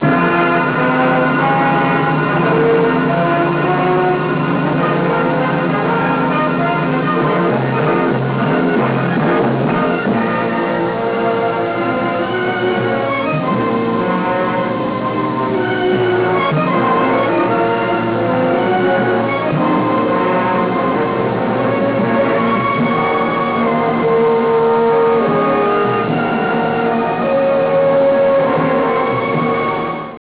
Dialogo
doppiaggio dell´epoca -